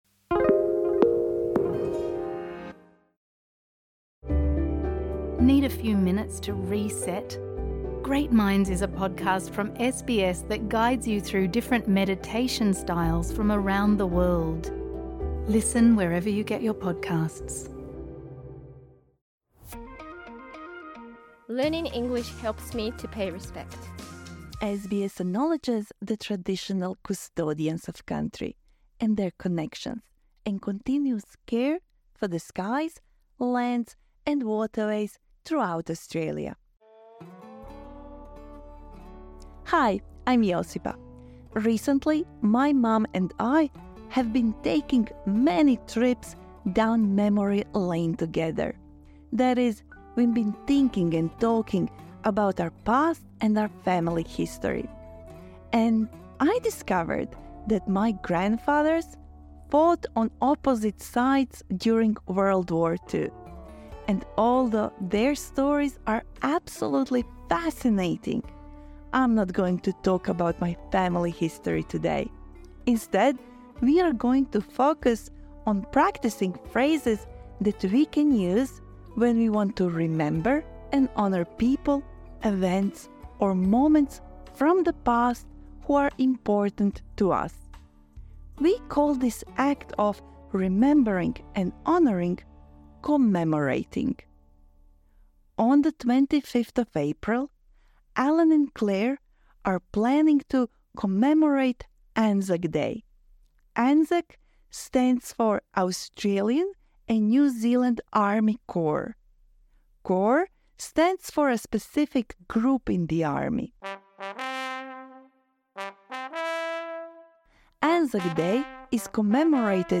This lesson suits advance learners.